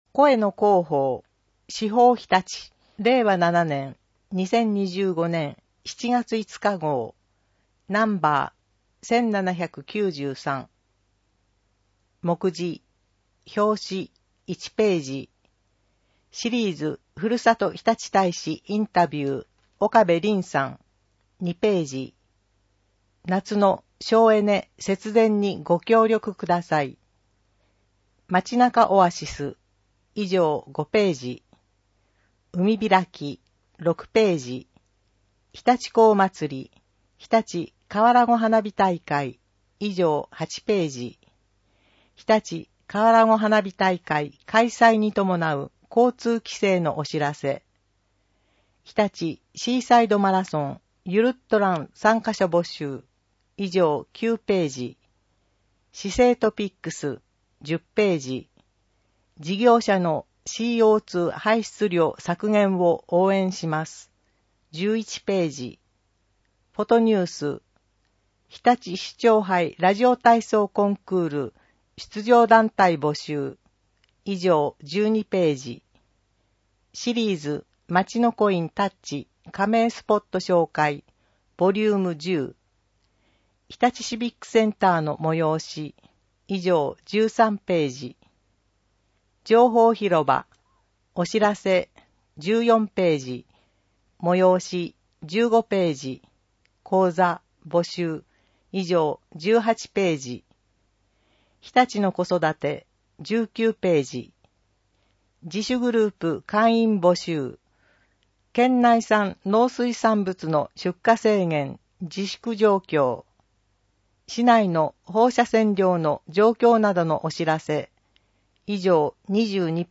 声の市報を読みあげます。